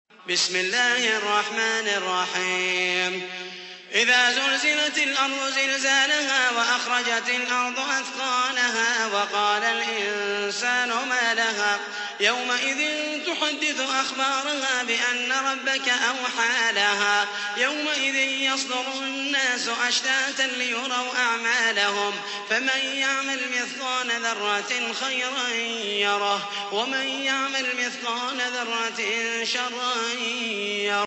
تحميل : 99. سورة الزلزلة / القارئ محمد المحيسني / القرآن الكريم / موقع يا حسين